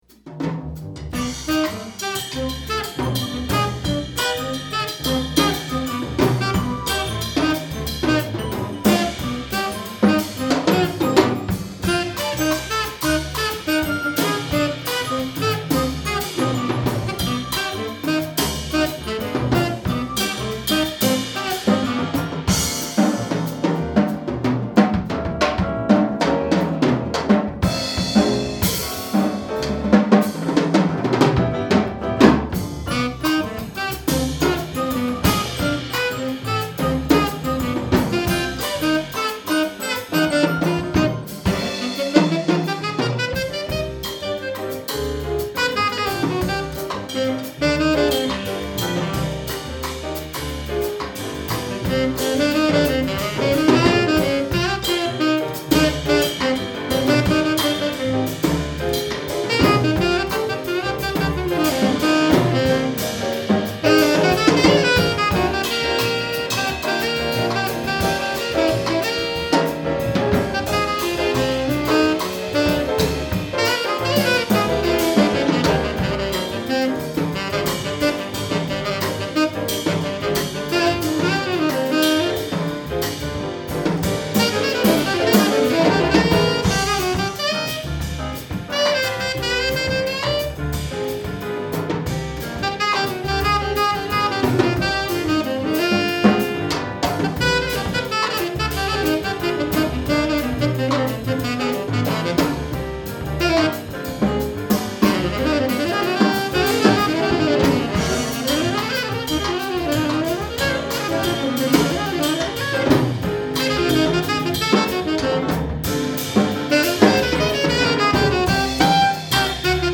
Jazz
Piano Quartet